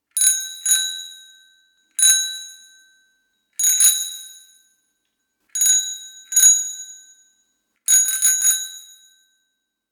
Bicycle call.
bell bicycle call ding hand-bell jingle metal metallic sound effect free sound royalty free Sound Effects